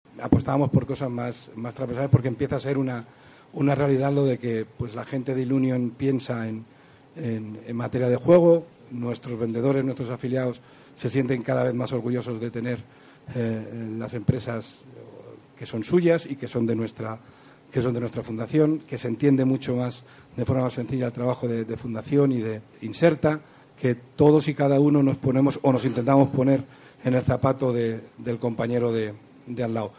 Los pasados 30 de junio y 1 de julio se celebró en Madrid reunión del Comité de Coordinación General (CCG) de nuestra Organización.